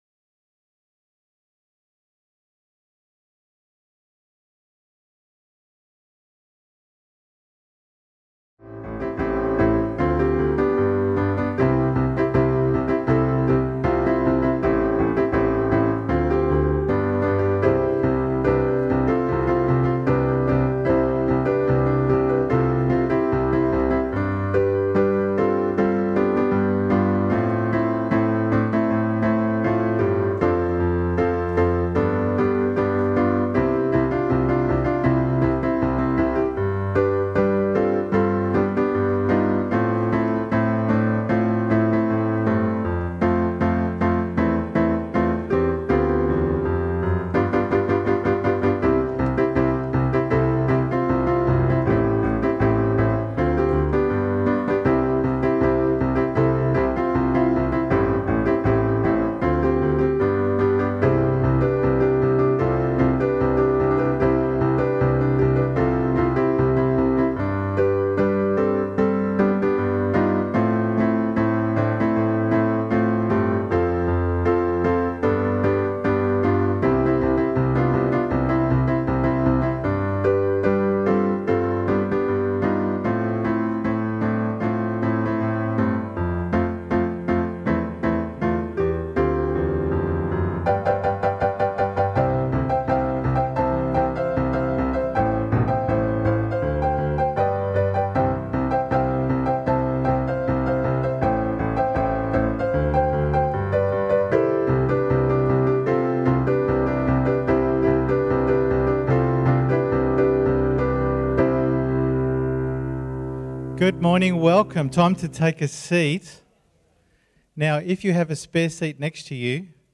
Sunday_Meeting_1st_November_2020_Audio.mp3